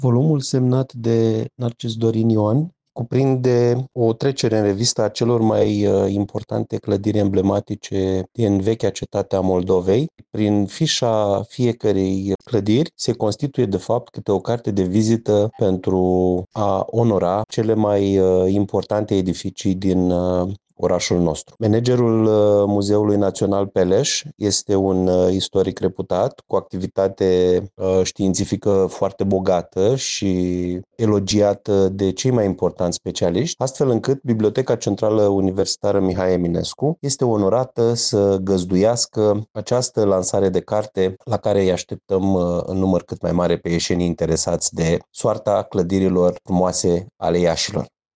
Într-o declarație pentru Radio România Iași